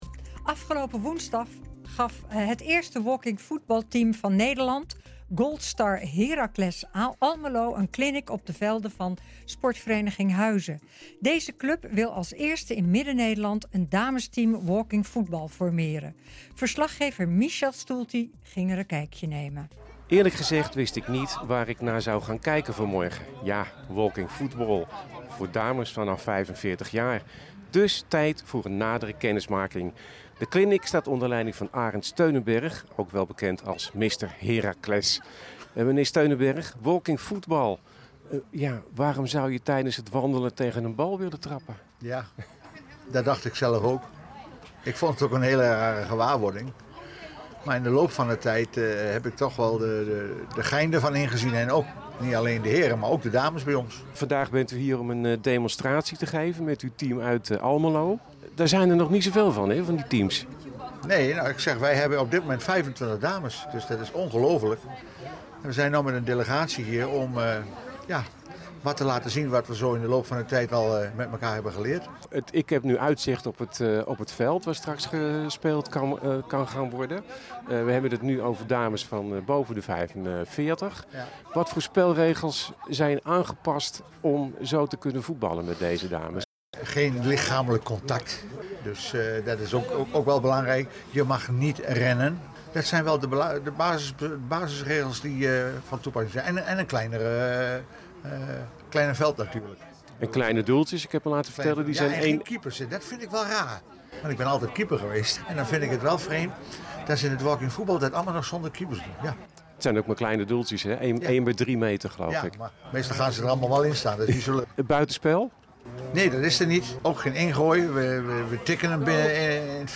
NH Gooi Zaterdag - Reportage Goldstar Heracles Almelo geeft Walking Football clinic bij SV Huizen